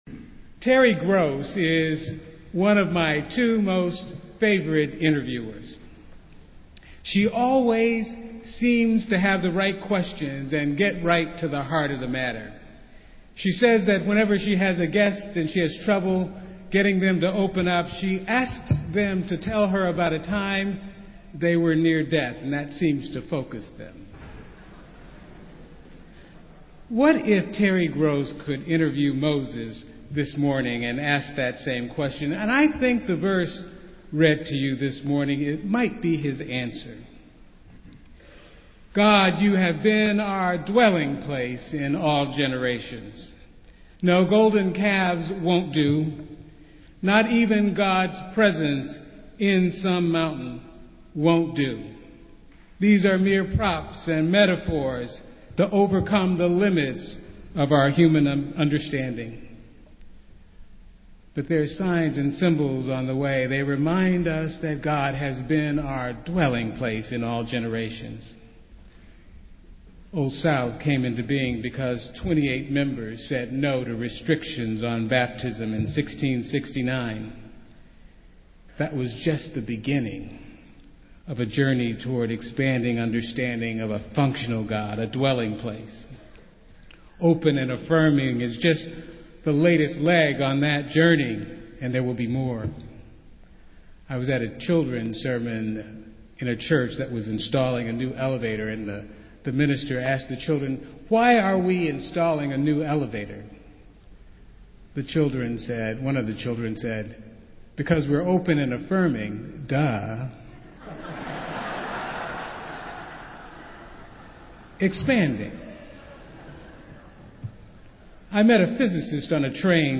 Festival Worship - Hymn Festival Sunday